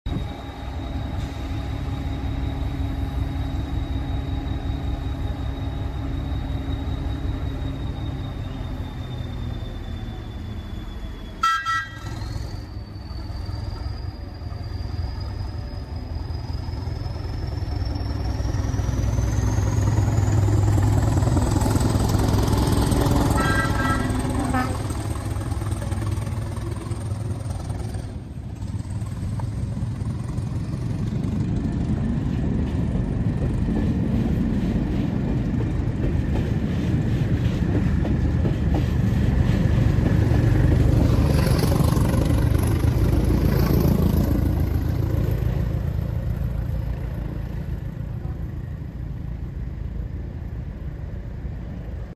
Hastings DEMU Mp3 Sound Effect Give it some! Hastings DEMU 1001 shows that she’s still got it, thrashing out of Basingstoke station.